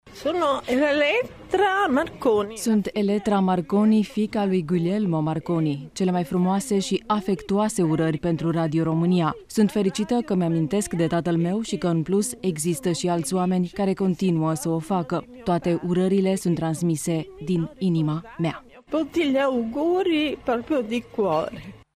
Prinţesa Eletrra Marconi, fiica lui Guglielmo Marconi, inventatorul radioului, a ţinut să felicite Radio România, la 88 de ani de la înfiinţare: